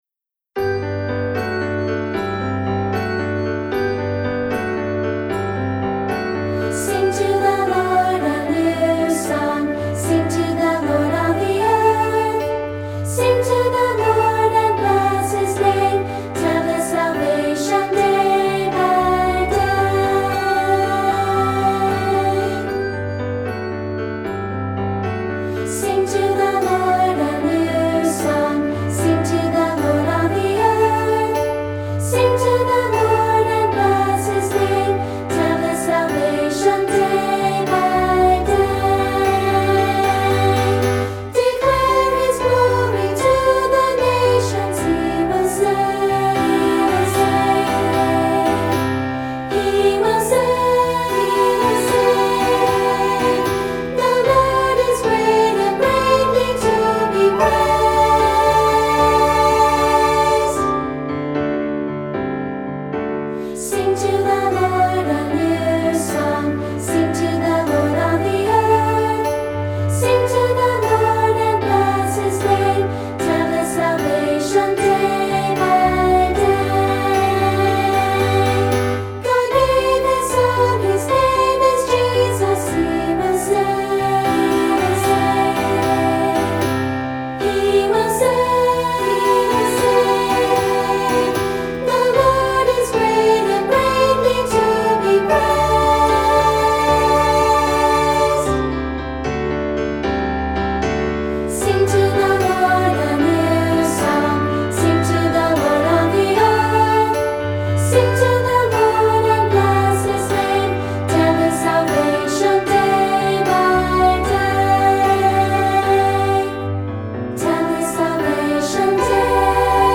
Voicing: Unison/2-Part, opt. Handbells, opt. Tambourine